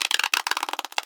creak.mp3